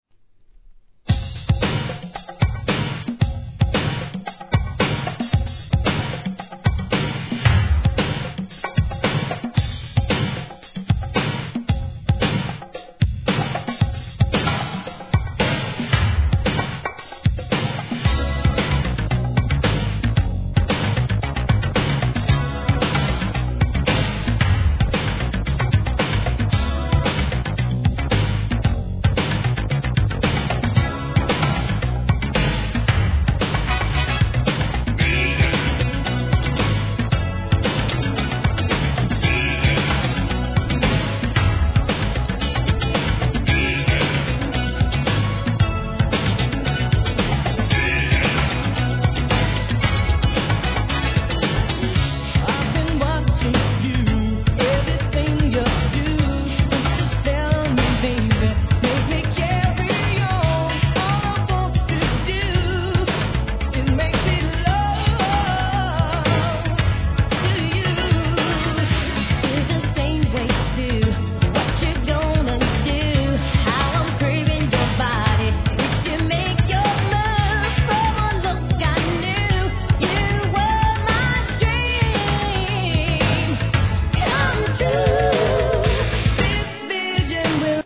NEW JACK SWING